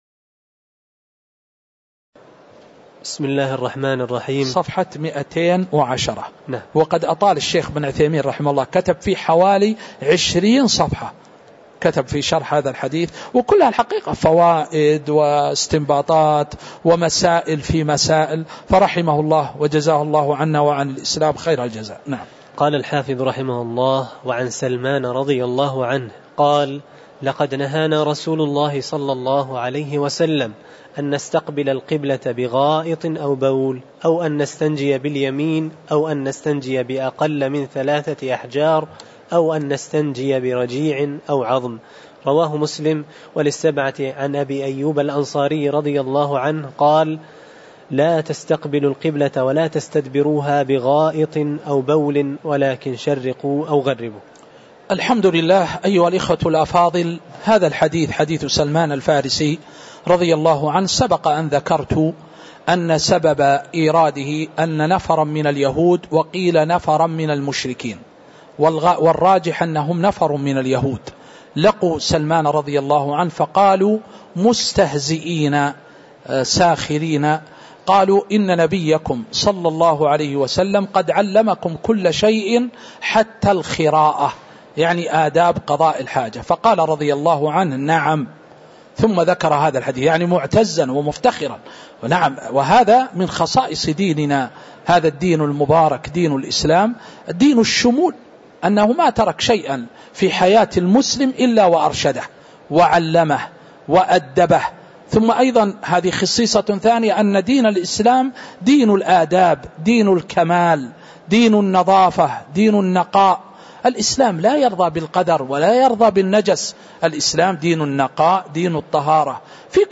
تاريخ النشر ١٣ ذو الحجة ١٤٤٤ هـ المكان: المسجد النبوي الشيخ